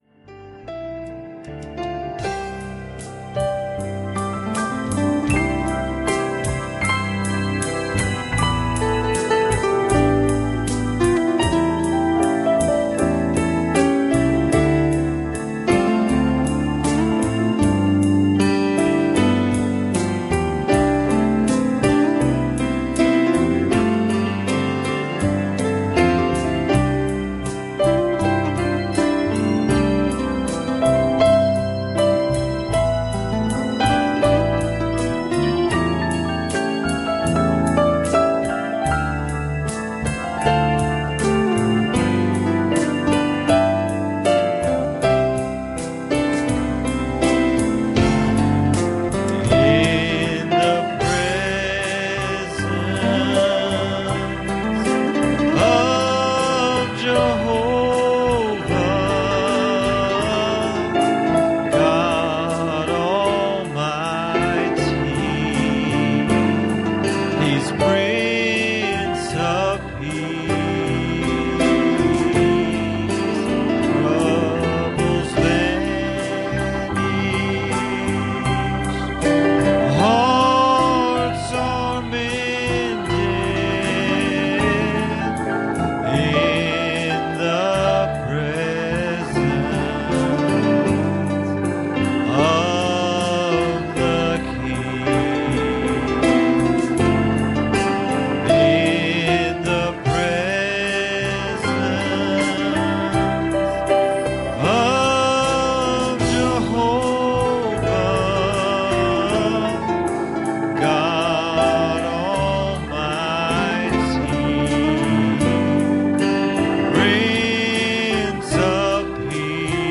Service Type: Wednesday Evening